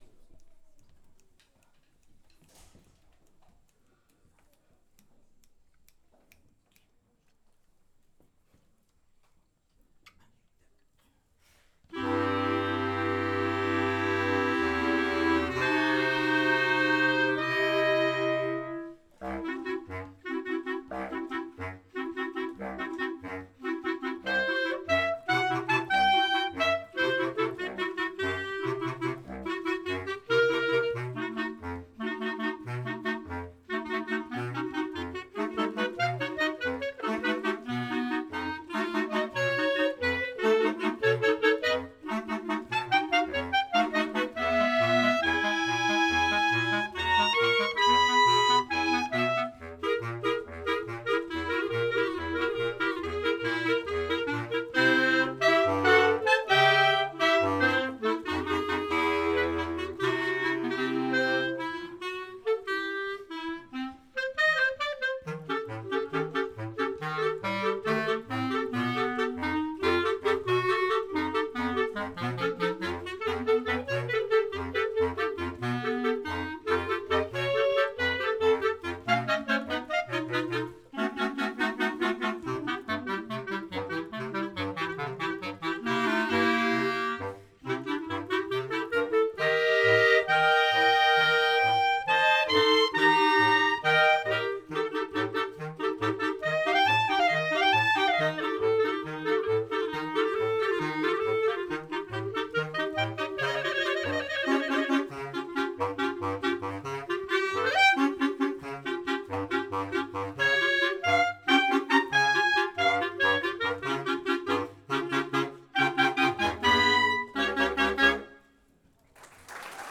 Cela donne notamment l’occasion à l’ensemble de clarinettes de présenter les morceaux que nous avons travaillés…